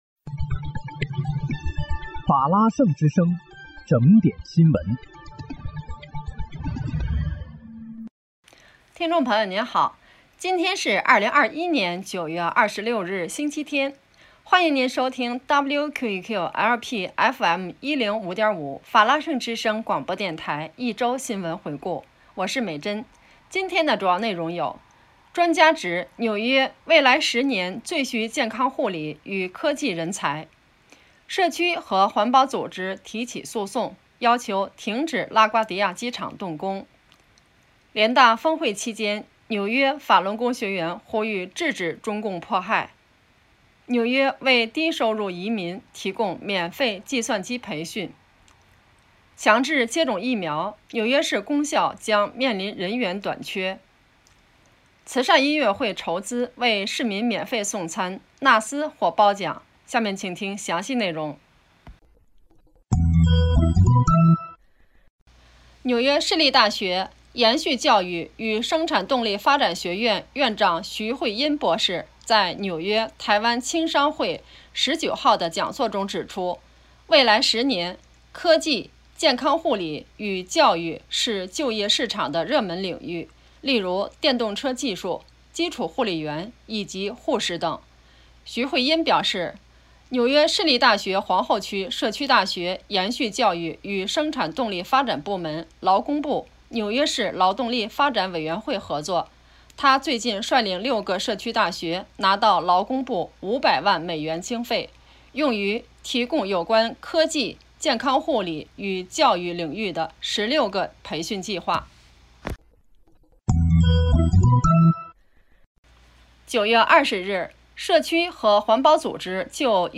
9月26日（星期六）纽约整点新闻